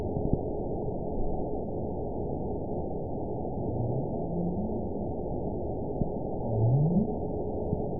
event 922221 date 12/28/24 time 11:09:29 GMT (11 months, 1 week ago) score 8.96 location TSS-AB04 detected by nrw target species NRW annotations +NRW Spectrogram: Frequency (kHz) vs. Time (s) audio not available .wav